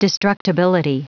Prononciation du mot destructibility en anglais (fichier audio)
destructibility.wav